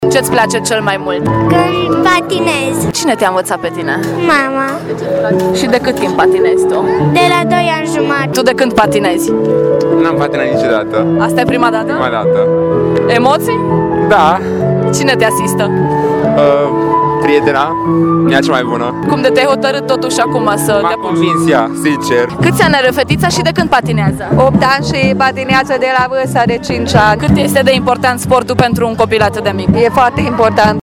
stiri-7-dec-vox-patine.mp3